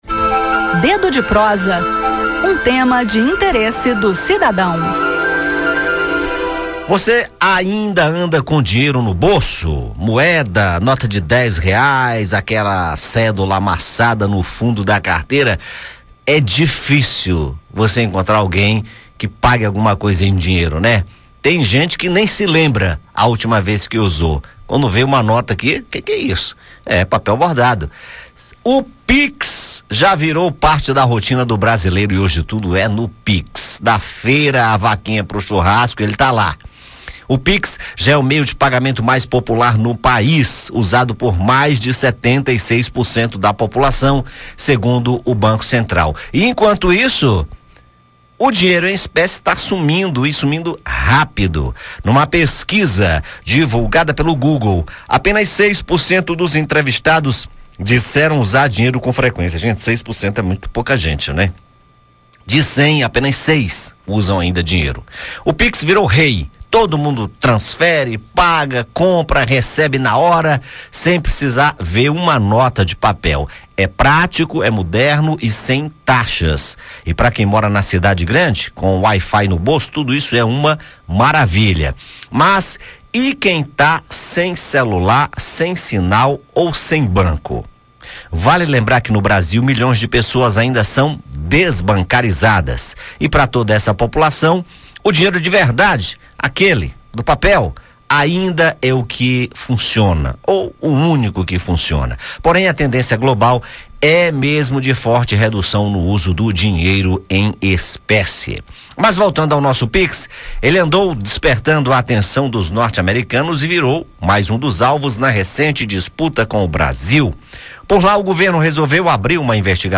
No bate-papo de hoje, voltamos a falar do assunto, após a polêmica surgida com a tentativa do governo Trump em abrir uma investigação comercial contra o Brasil, alegando que o Pix é uma “concorrência desleal” por ser um sistema estatal gratuito.